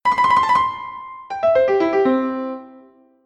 O tema principal fragmentao en dúas partes:
o trilo...
Fragmentacion_tema_comp.mp3